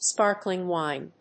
アクセントspárkling wíne